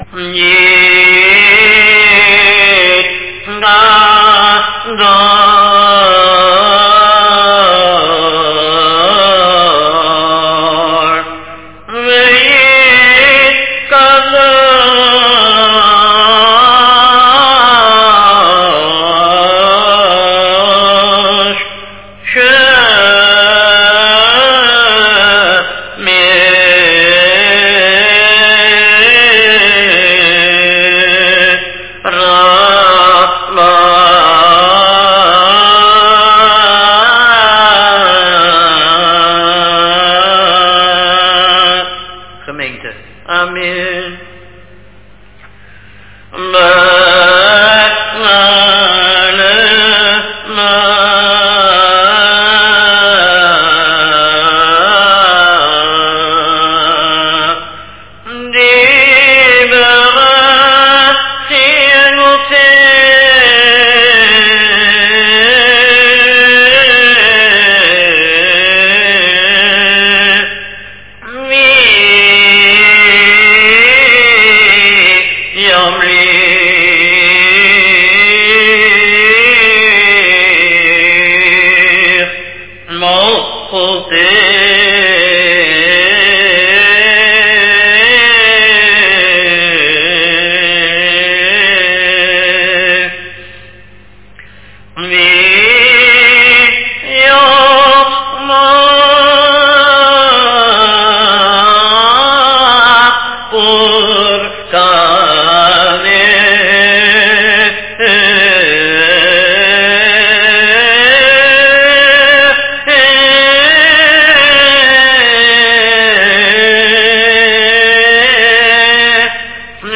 All Standing, chazzan sings.